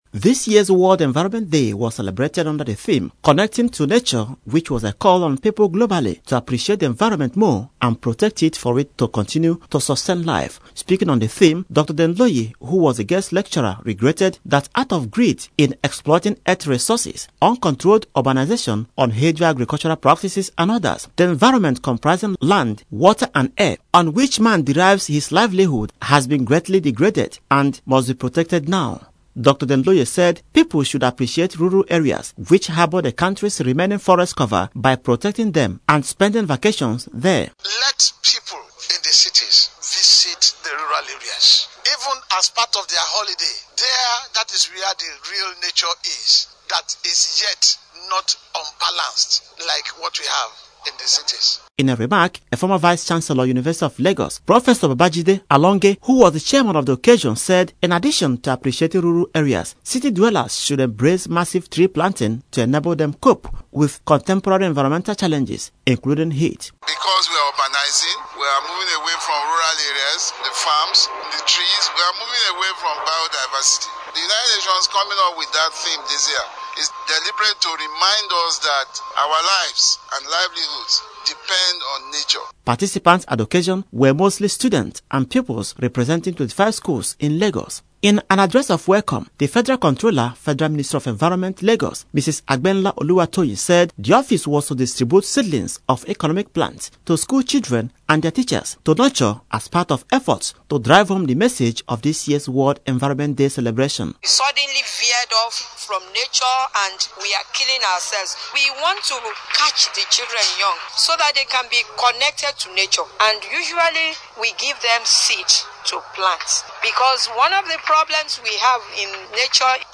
Radio Report: Living close to nature means better health
LAGOS-ENVIRONMENT-DAY-REPORT.mp3